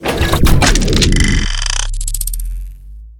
laserin.ogg